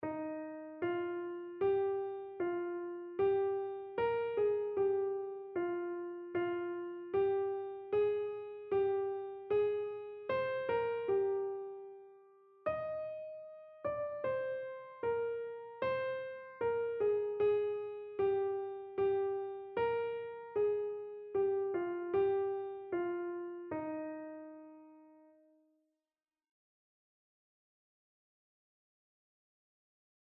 Einzelstimmen (Unisono)
• Sopran [MP3] 473 KB